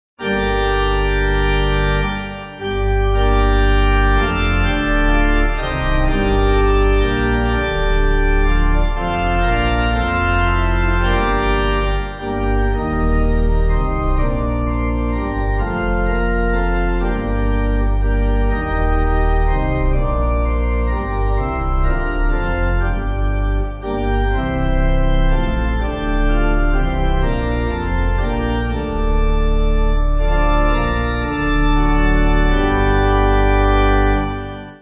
Organ
(CM)   4/Gm